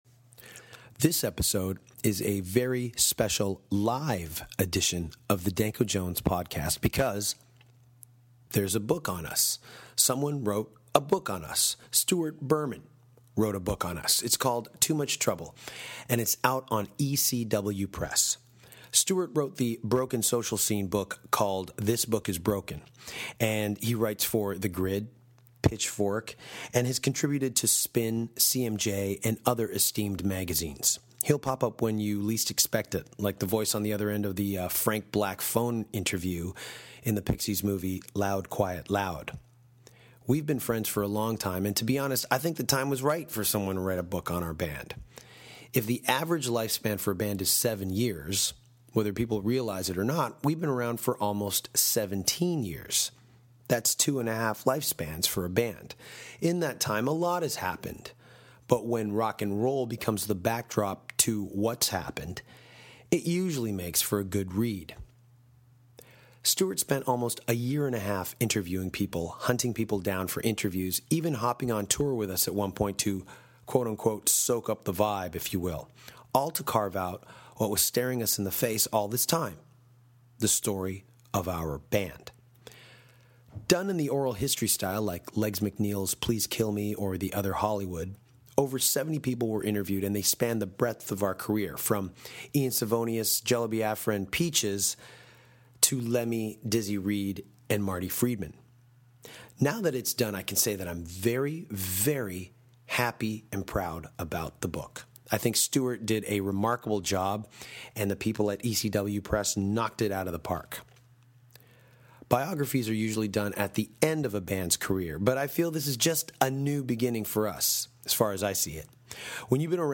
A Special Live Taping with guest host